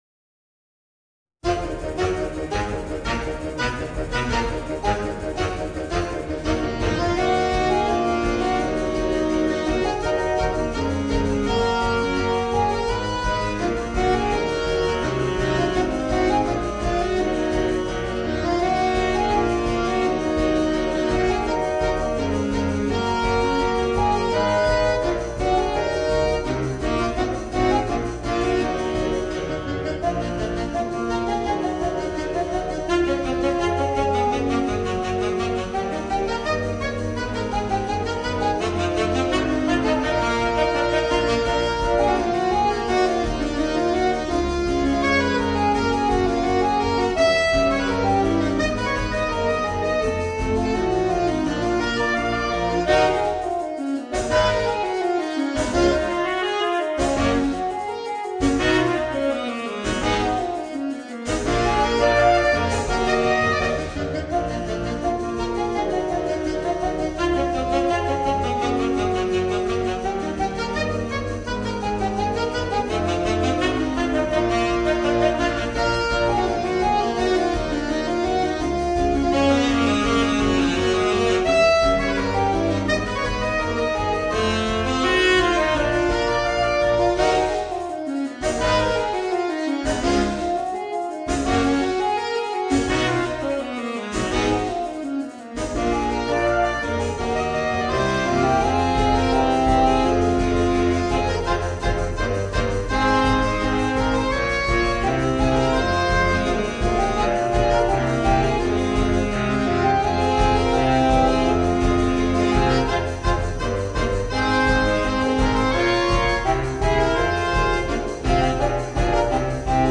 Voicing: 4 Saxophones